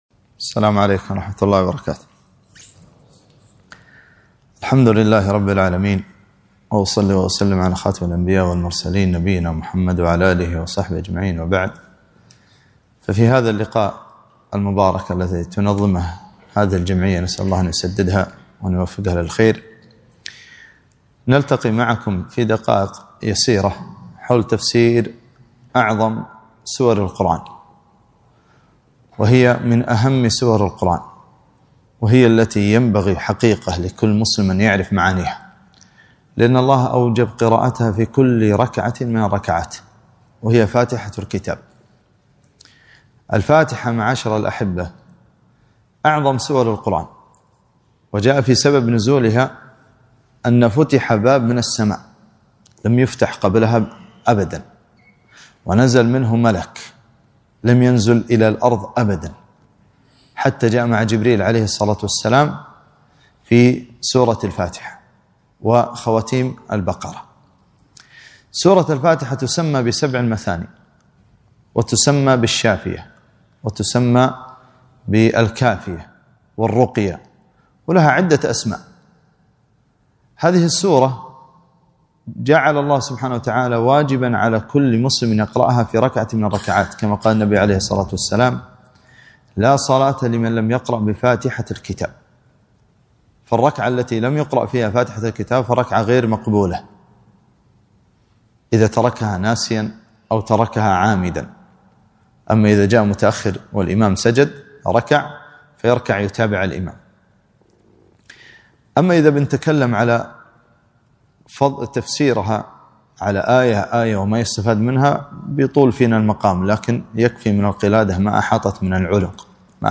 كلمة - تفسير سورة الفاتحة